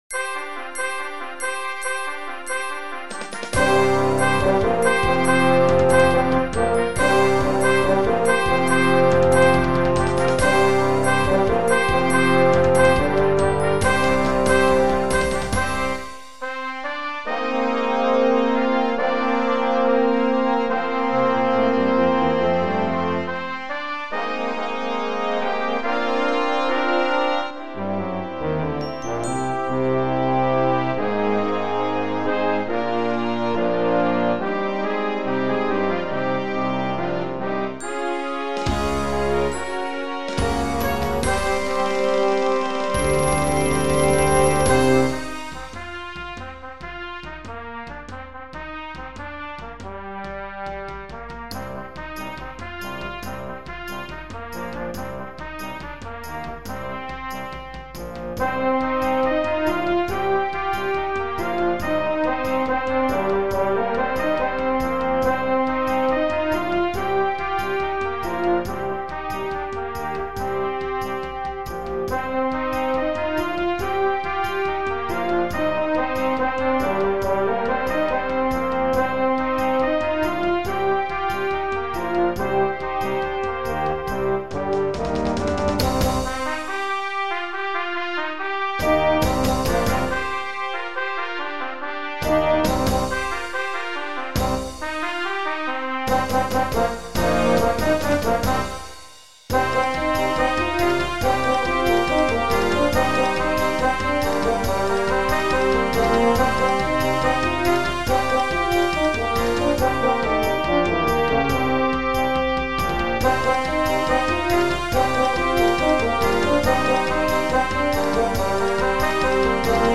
Voicing: 13 Brass